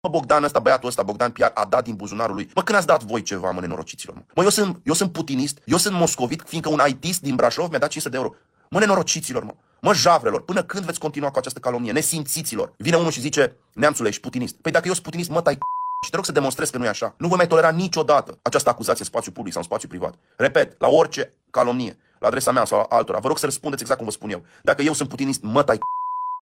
Într-un filmuleț distribuit pe rețelele de socializare, deputatul AUR i-a jignit pe cei care au lansat acuzații la adresa sa și i-a îndemnat pe urmăritorii lui să folosească același limbaj.